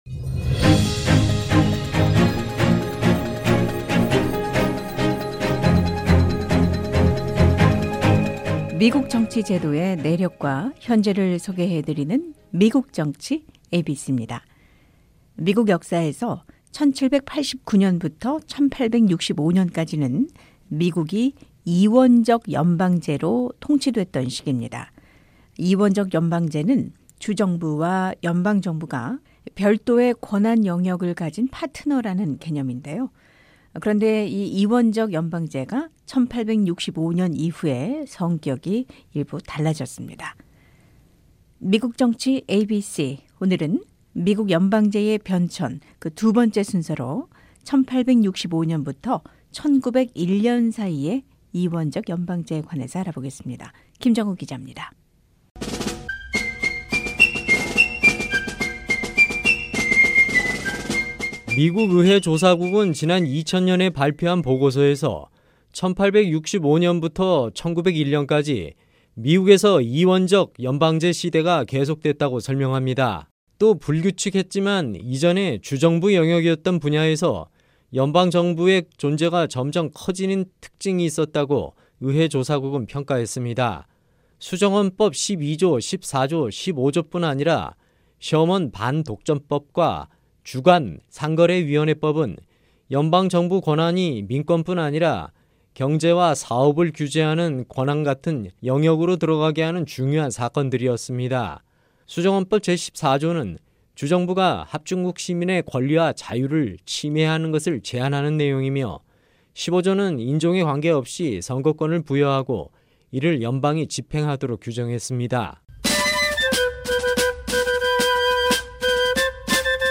기자가 전해 드립니다.